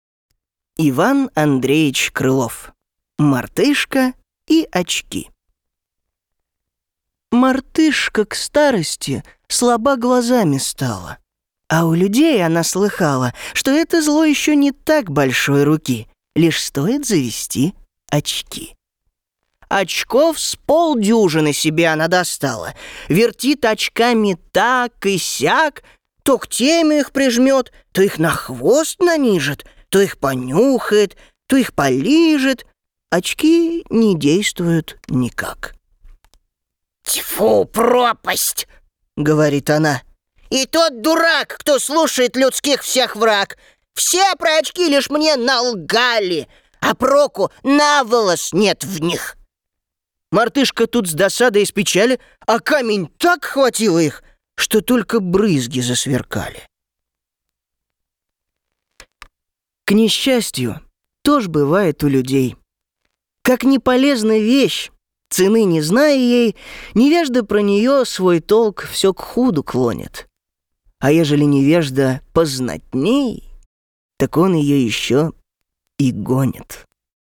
Тракт: Тракт: Микрофон: SHURE SM7B; Пред: LONG Voice Master; Карта (АЦП): Steinberg UR22mkII
Необычный, нестандартный.